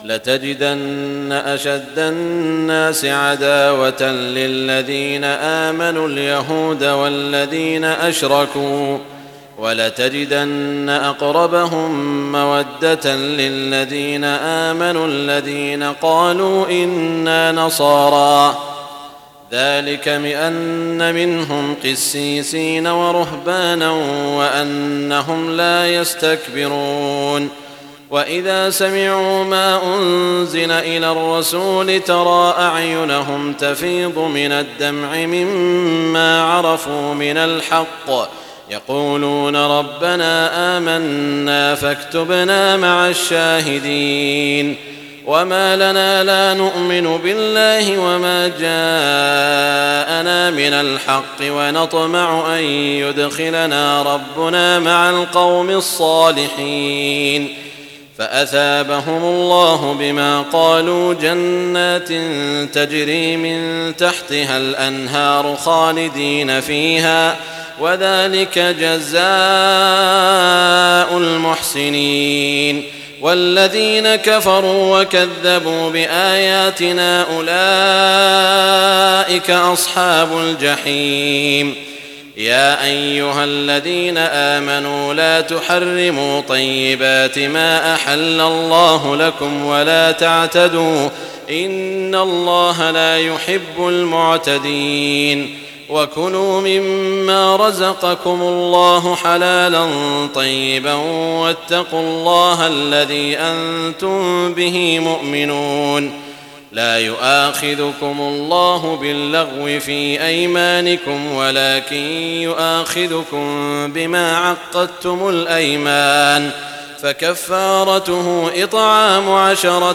تهجد ليلة 27 رمضان 1419هـ من سورتي المائدة (82-120) و الأنعام (1-58) Tahajjud 27 st night Ramadan 1419H from Surah AlMa'idah and Al-An’aam > تراويح الحرم المكي عام 1419 🕋 > التراويح - تلاوات الحرمين